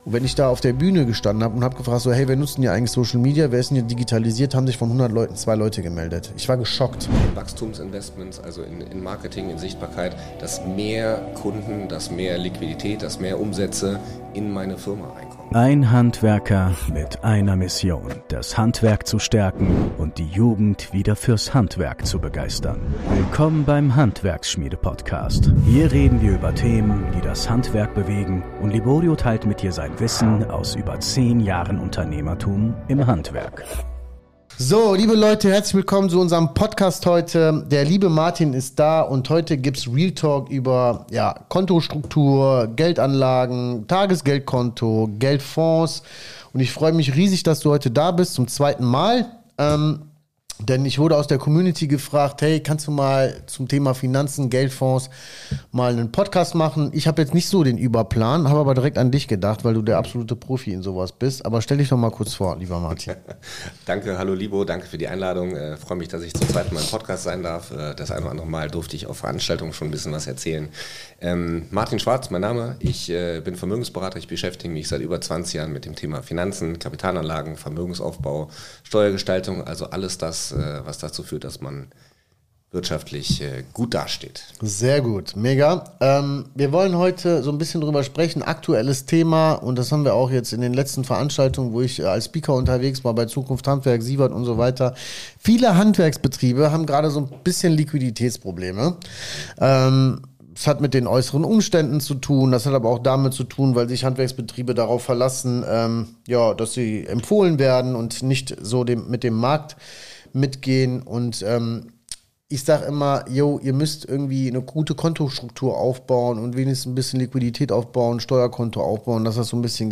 Was du als Handwerksbetrieb gegen Liquiditätsprobleme machen kannst | Interview